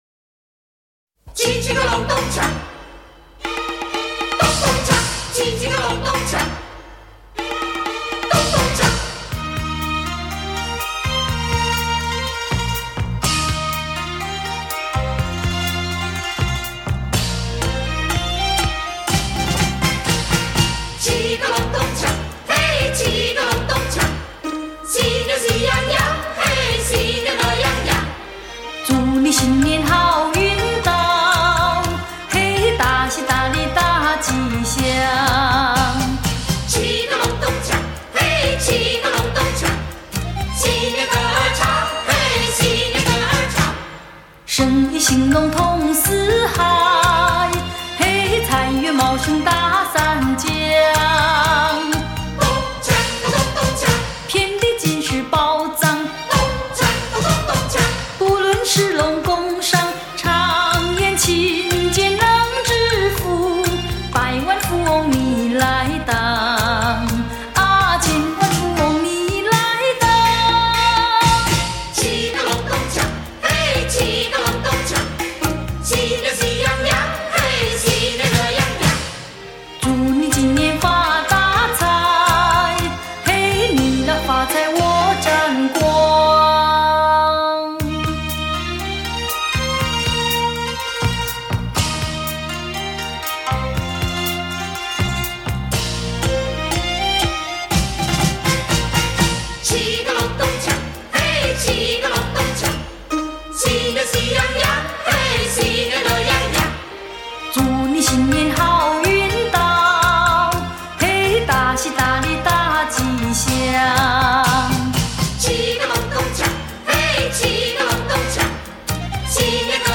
华语世界最具本土代表性的畅销女歌手
留下朴实不华的声音在脑海中飞扬